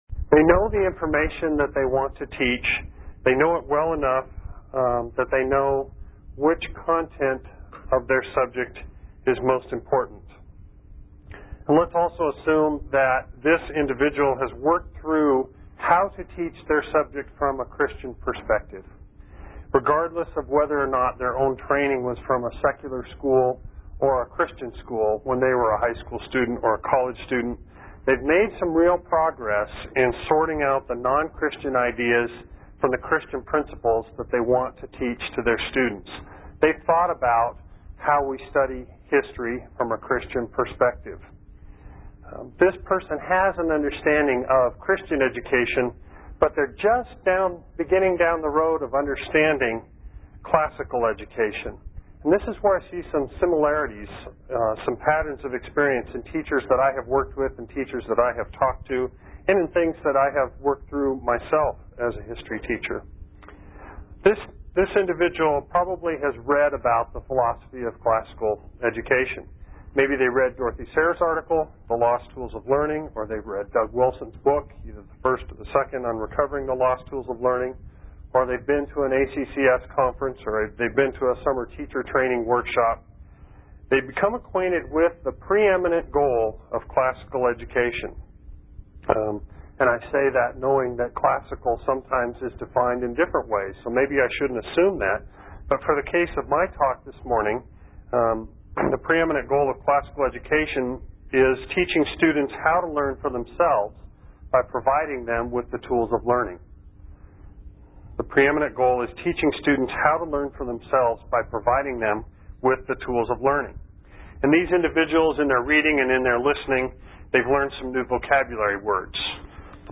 2003 Workshop Talk | 0:58:40 | All Grade Levels, History
The Association of Classical & Christian Schools presents Repairing the Ruins, the ACCS annual conference, copyright ACCS.